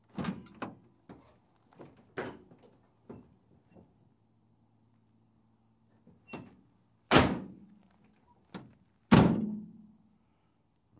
货车后门
描述：后部并排门打开和关闭。 Rode NTG1,16 bit 48KHz
声道立体声